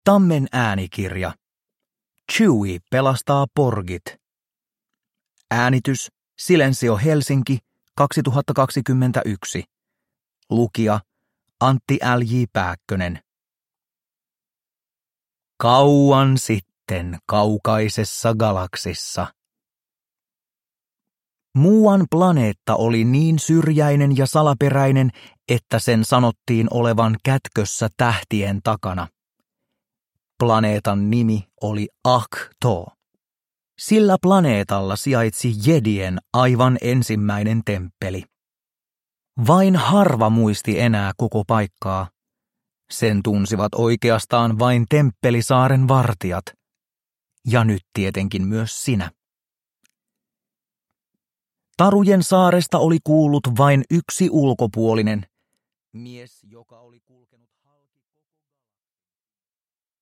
Uppläsare: Antti L. J. Pääkkönen, Joonas Suotamo